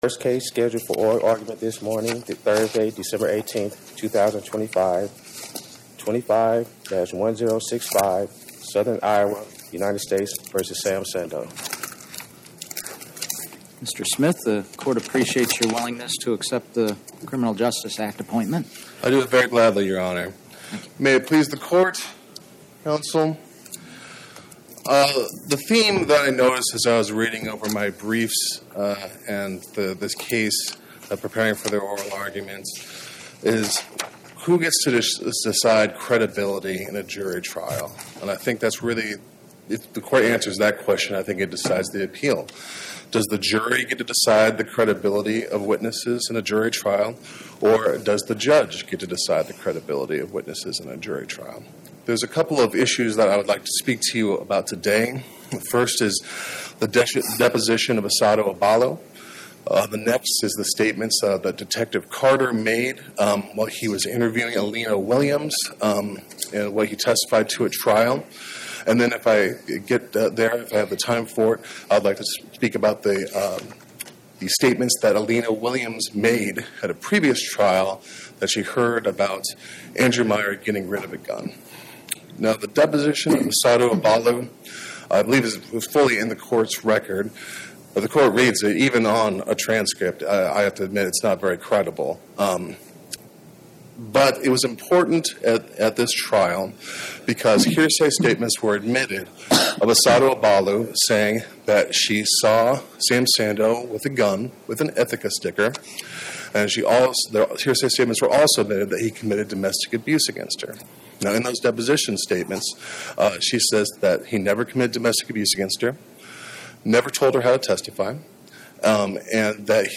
Oral argument argued before the Eighth Circuit U.S. Court of Appeals on or about 12/18/2025